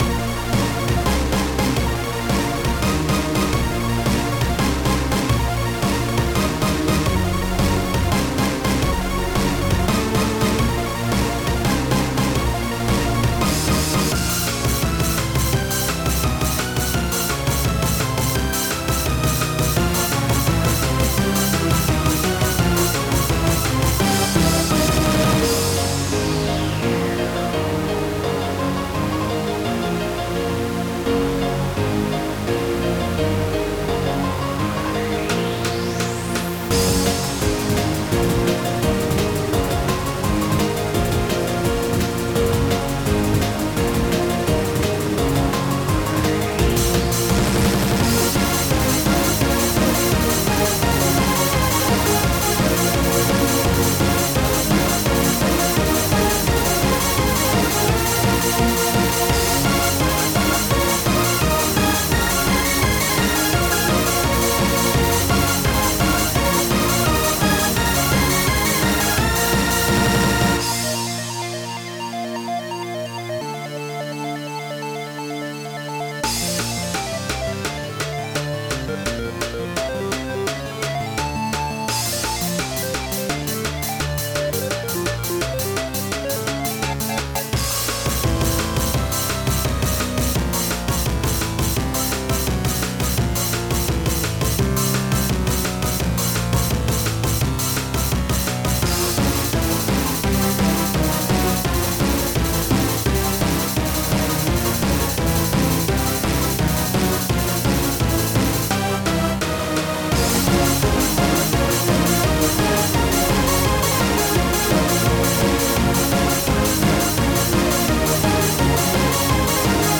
Hard Chiptune remix